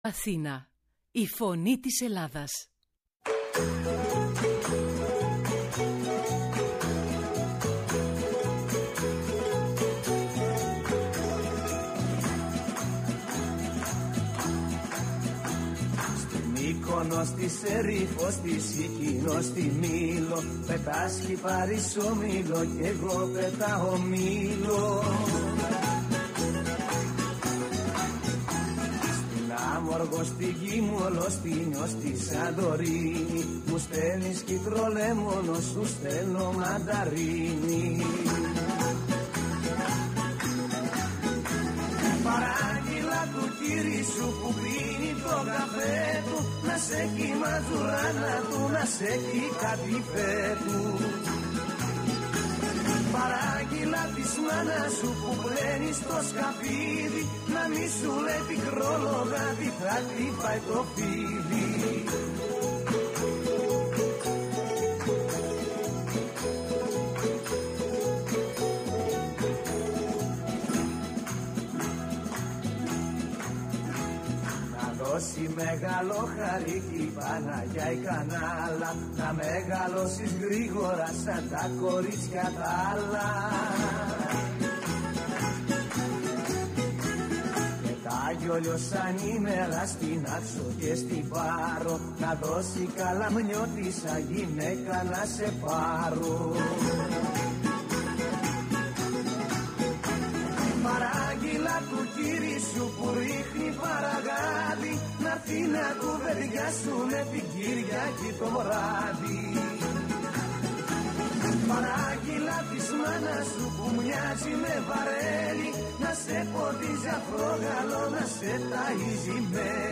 Η Ιρλανδία, η αποκαλούμενη «κέλτικη» τίγρης με την χαμηλή φορολογία που προσελκύει χιλιάδες ‘Ελληνες, ήταν στο επίκεντρο της εκπομπής «Η Παγκόσμια Φωνή μας» στο ραδιόφωνο της Φωνής της Ελλάδας.